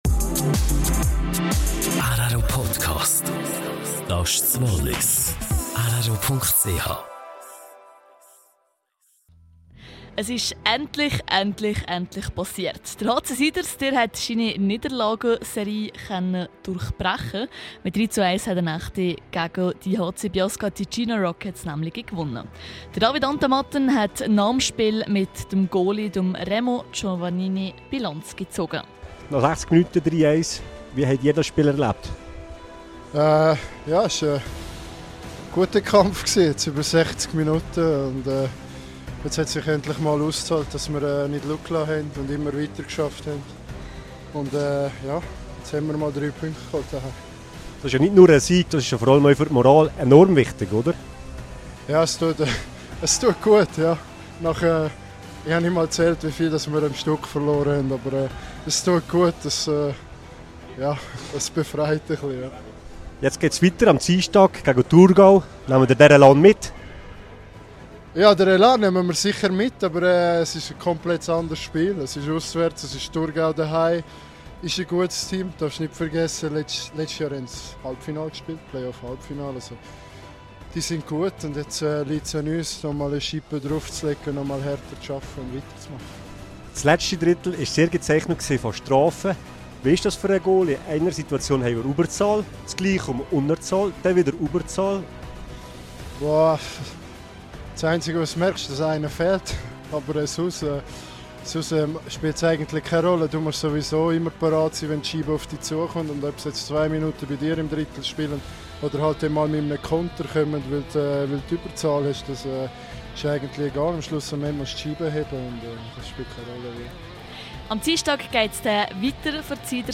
Der Pakt um die Sonne: Das sagen die Besucher zur Premiere.